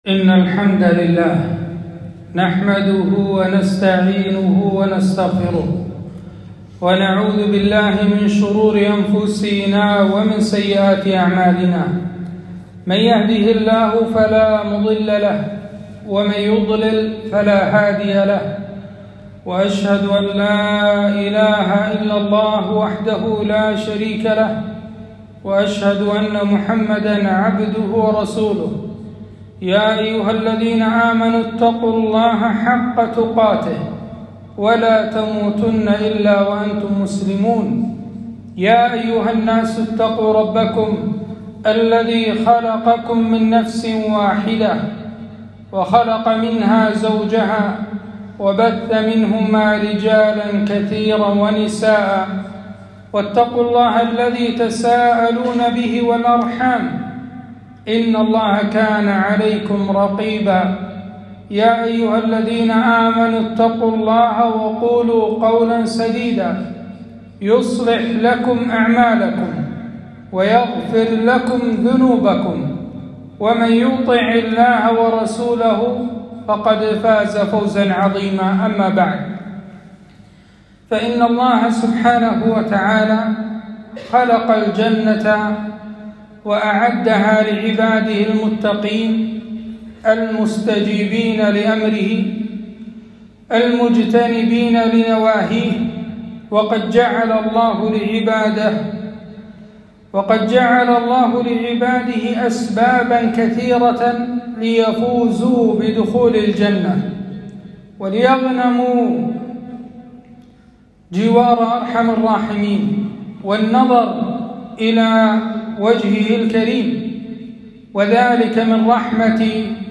خطبة - من أعظم أسباب دخول الجنة